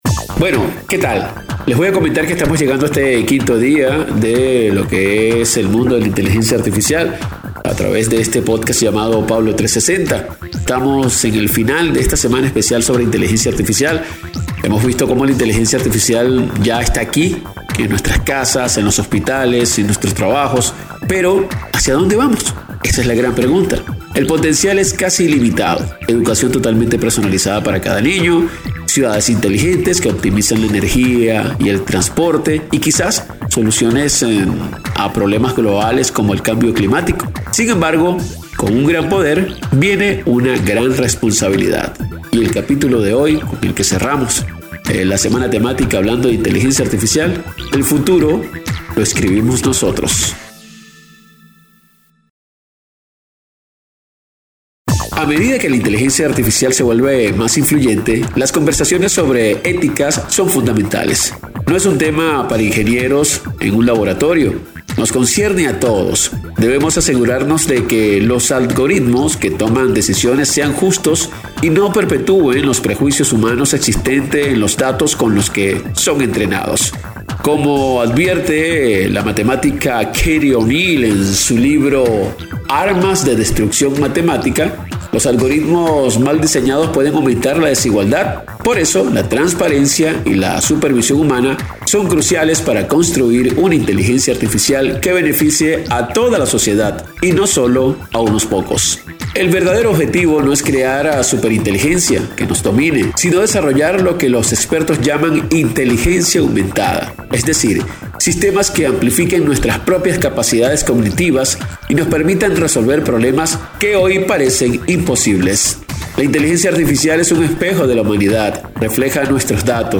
El programa, transmitido por Max FM y La Voz de Carabobo, se alejó de las aplicaciones prácticas para centrarse en las decisiones humanas que guiarán esta poderosa tecnología.